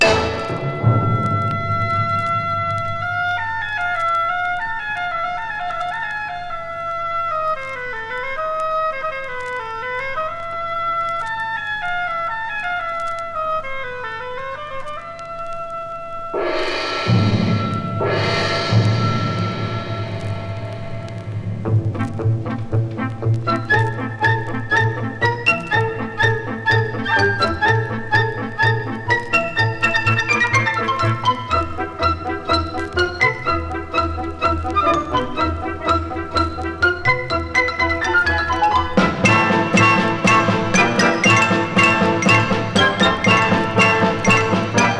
Classical, Easy Listening, World　Japan　12inchレコード　33rpm　Mono